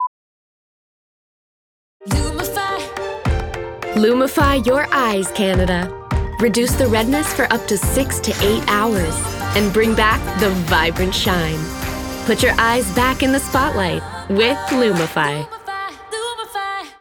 Commercial (Lumify) - EN